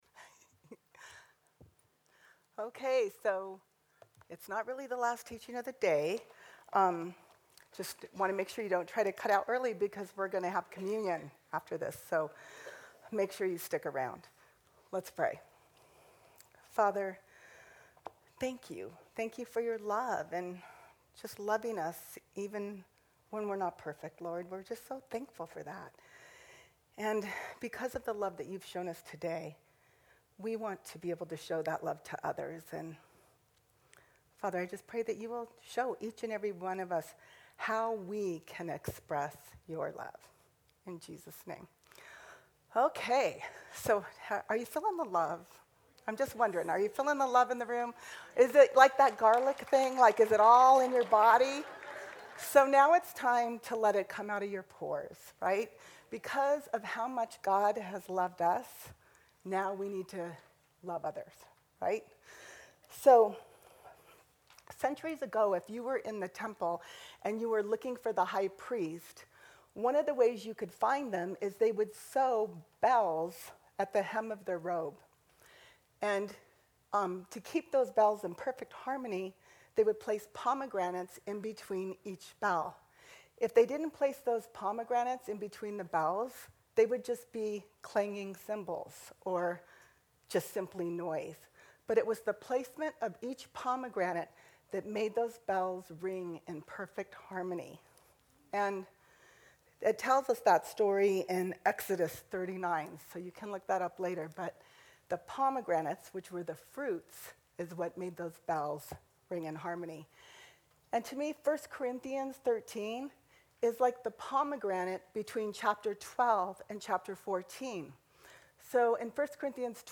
Women's Day Conference 2016: All You Need is Love